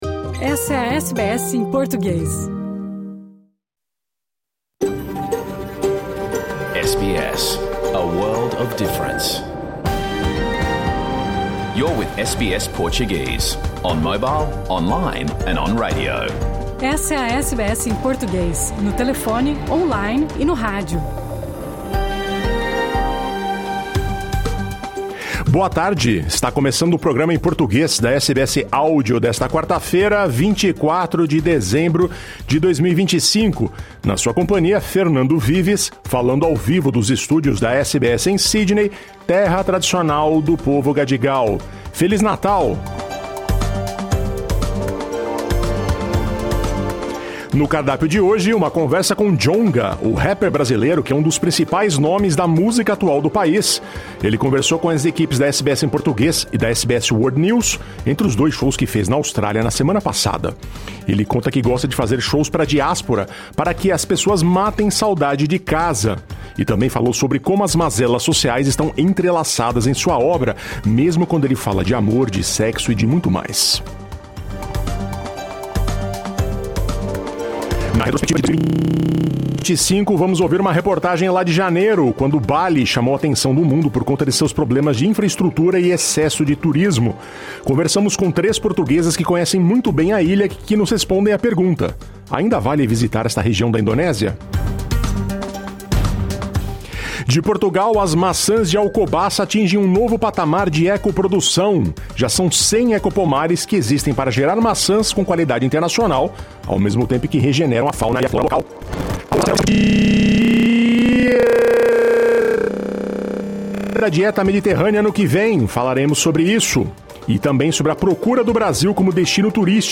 O programa que foi ao ar em rede nacional ao vivo pela rádio SBS 2 da Austrália. O noticiário do dia. Uma conversa com Djonga, o rapper brasileiro que é um dos principais nomes da música atual do país, e que esteve na Austrália para dois shows. Na retrospectiva do ano, portugueses que conhecem Bali a fundo comentam sobre os problemas e desafios de infraestrutura da ilha, um dos principais destinos turísticos a quem vive na Austrália.